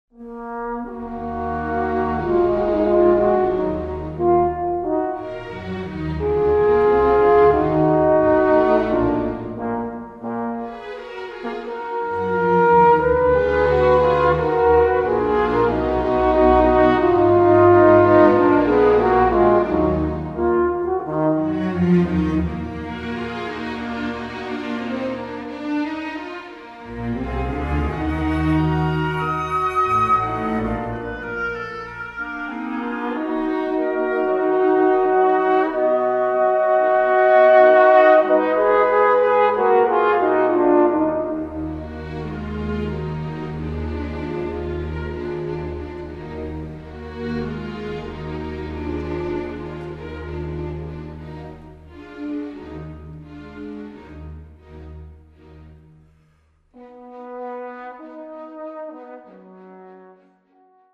Besetzung: Instrumentalnoten für Saxophon